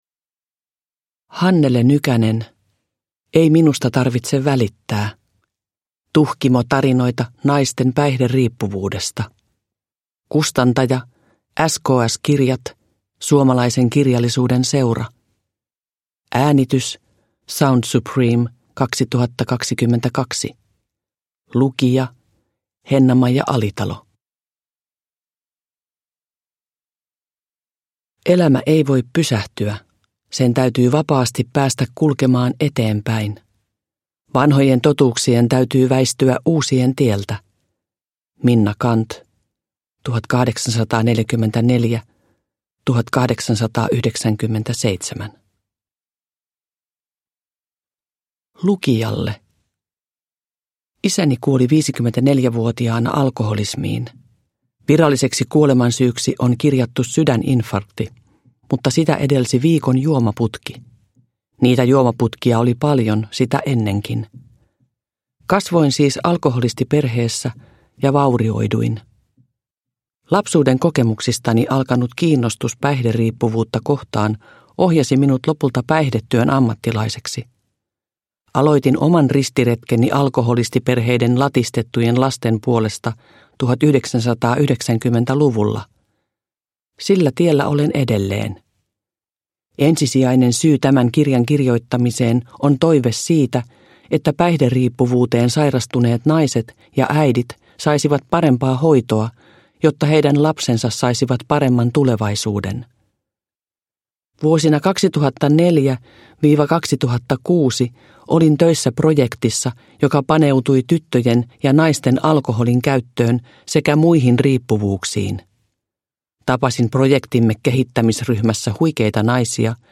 Ei minusta tarvitse välittää – Ljudbok – Laddas ner